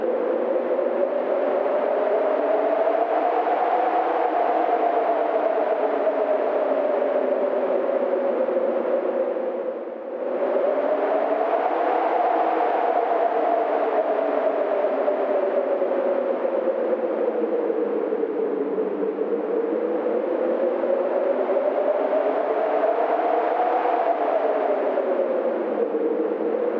heavyWind.ogg